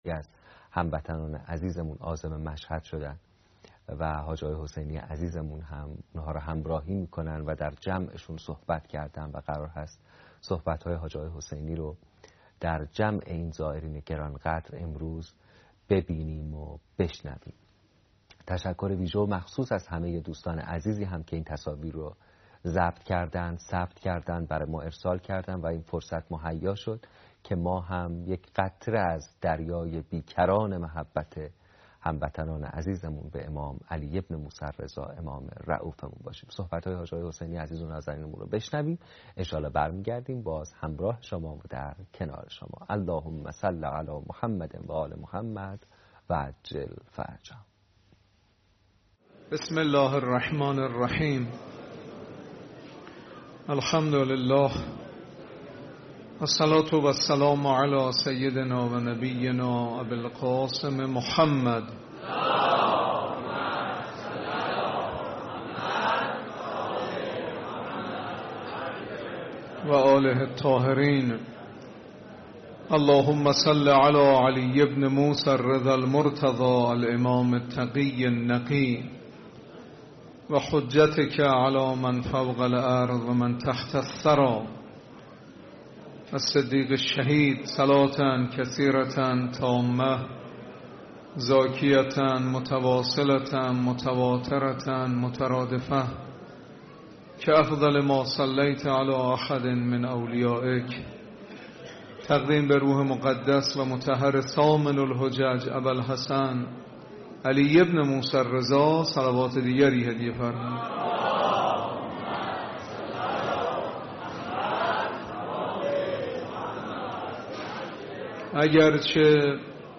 در جمع زیارت اولیهای مشهد مقدس